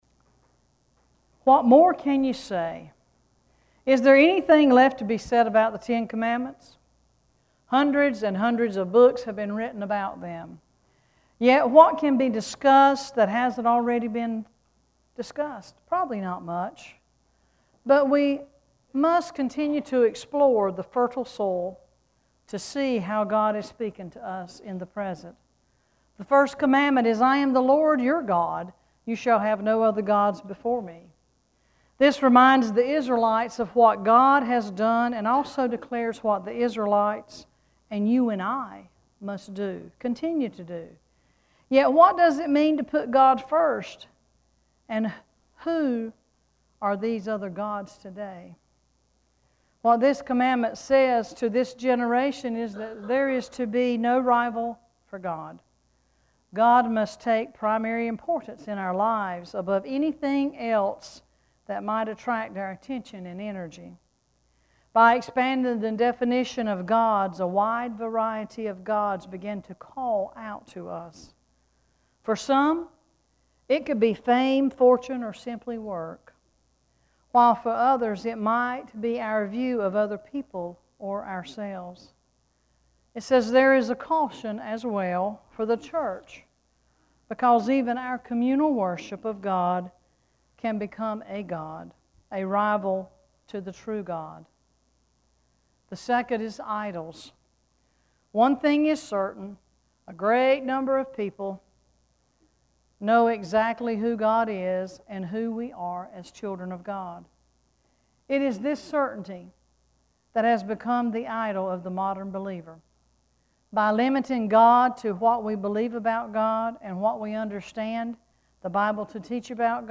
10-8-sermon.mp3